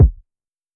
kick 4.wav